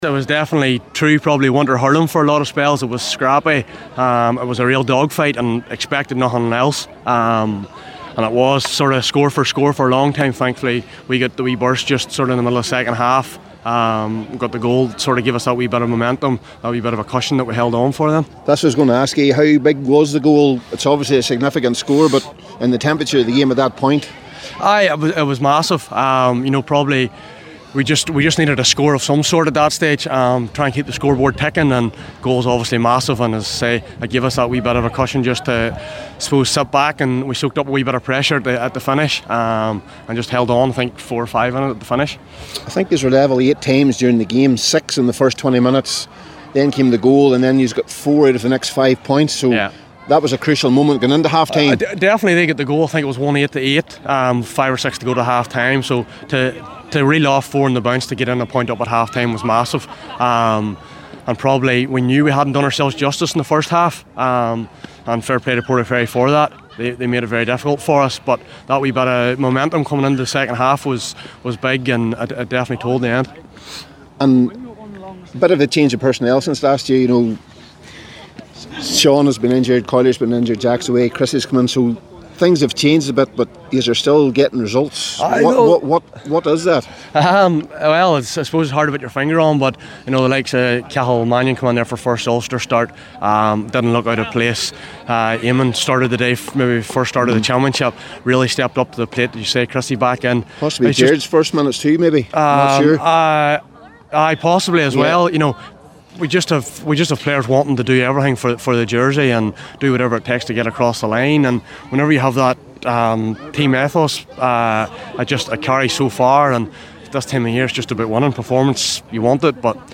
After the game,